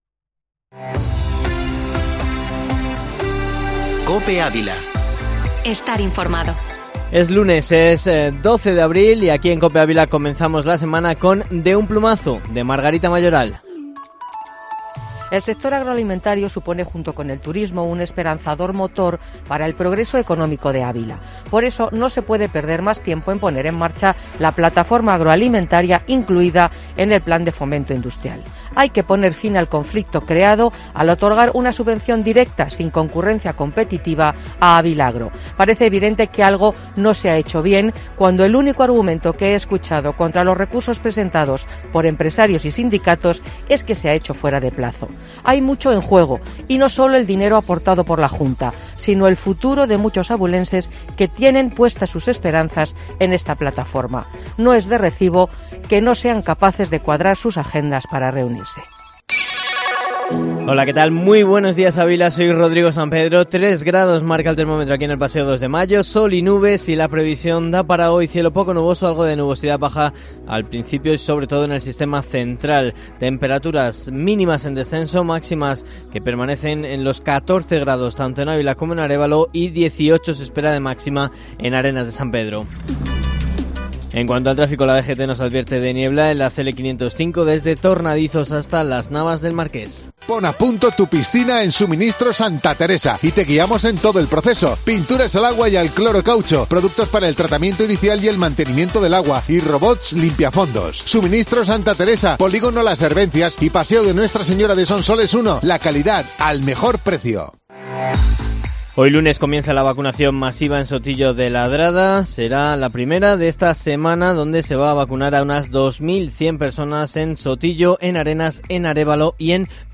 Informativo matinal Herrera en COPE Ávila 12/04/2021